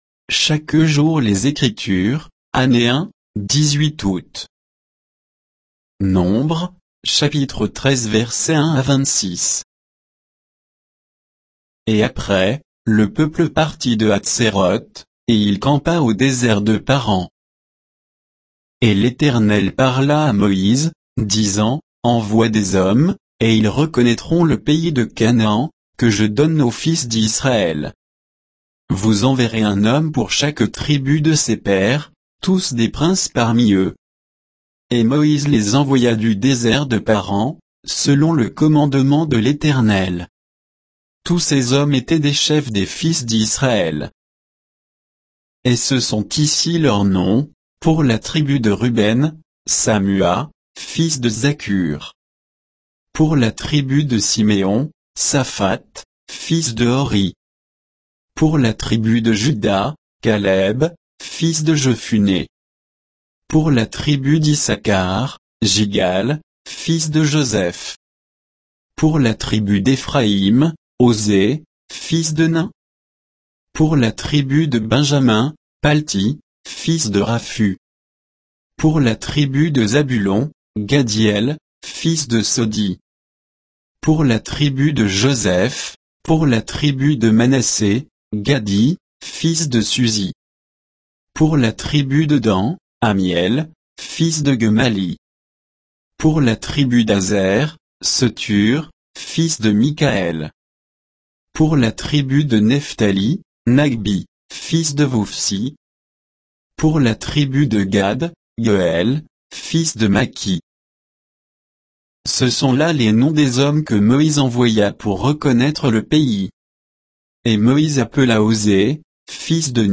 Méditation quoditienne de Chaque jour les Écritures sur Nombres 13, 1 à 26